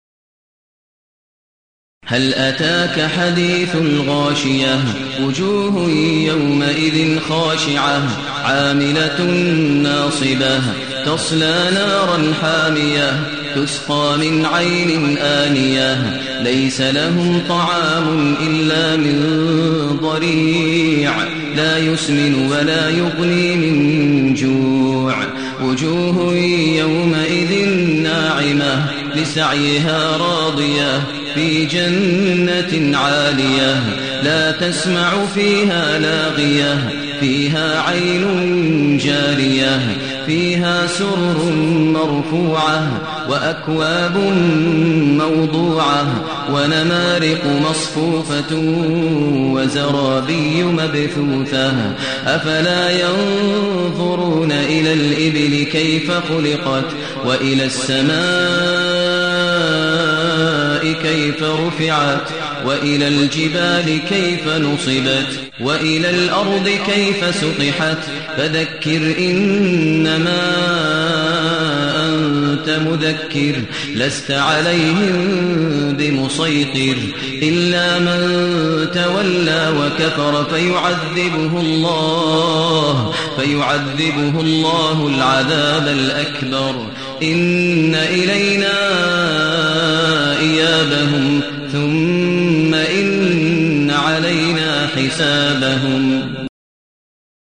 المكان: المسجد الحرام الشيخ: فضيلة الشيخ ماهر المعيقلي فضيلة الشيخ ماهر المعيقلي الغاشية